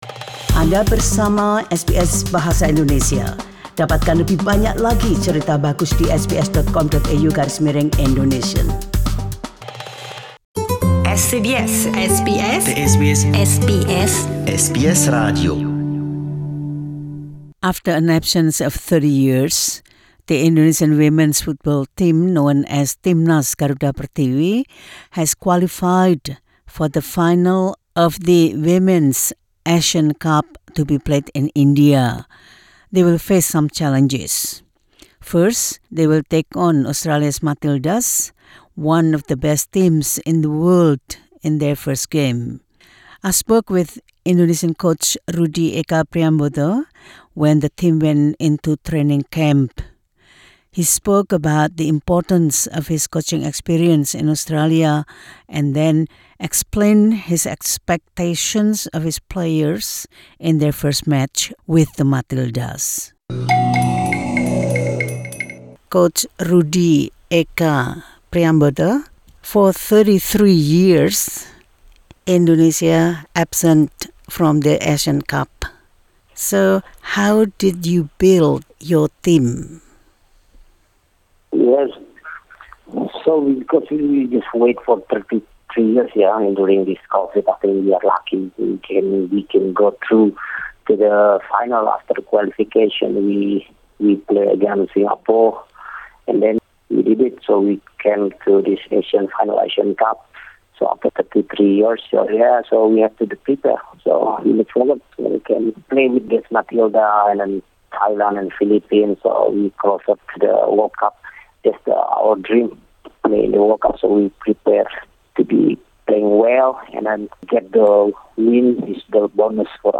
Dalam wawancara dalam bahasa Inggris ini